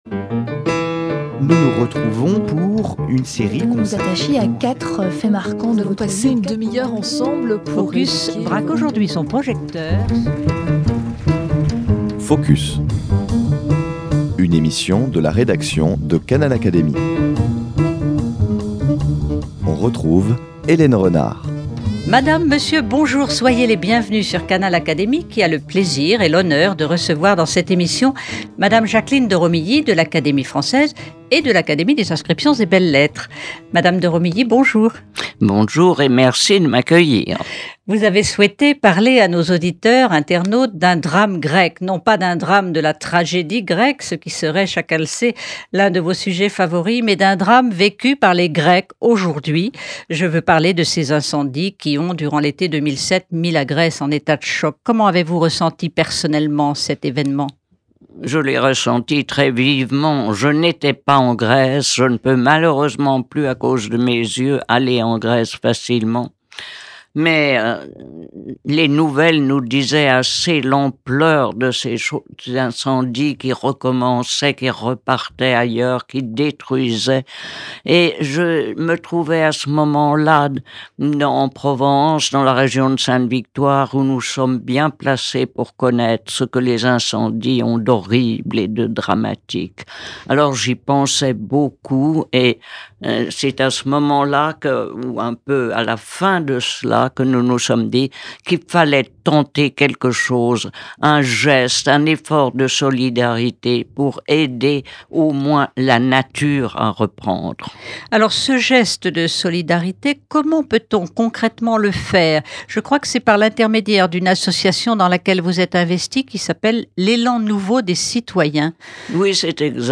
Jacqueline de Romilly a souhaité qu'une lecture soit donnée de ce passage :